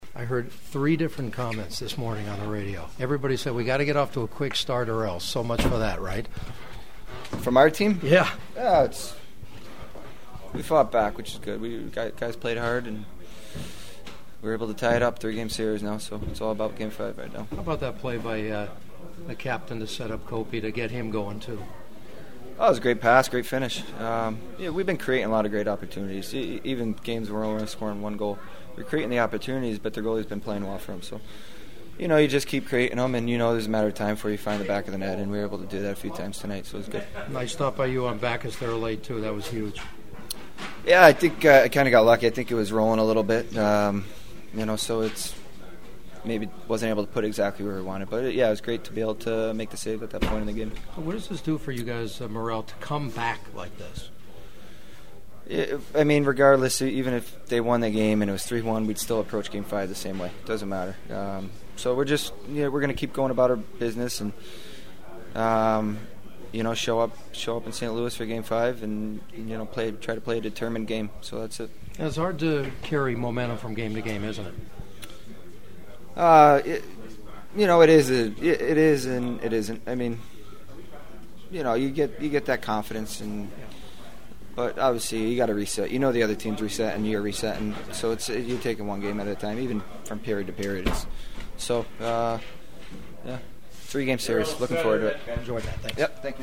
The following are my postgame chats from the locker room and they were all cautiously stoked about their chances during the rest of this series…
Kings goalie Jonathan Quick: